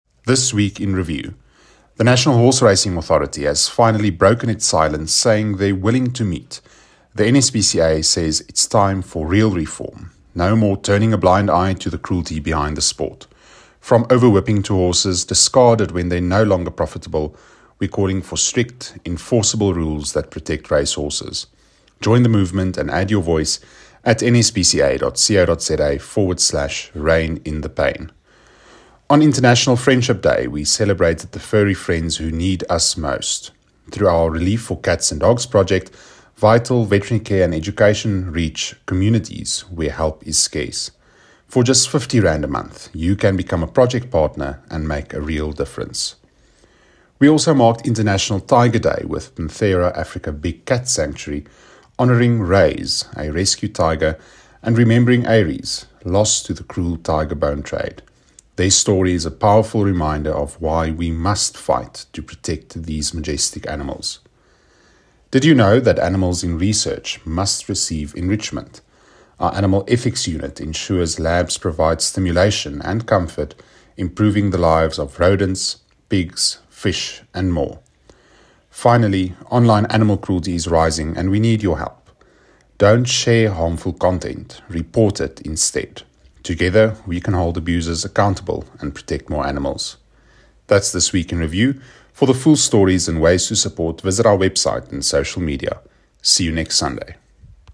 Voice-Over.mp3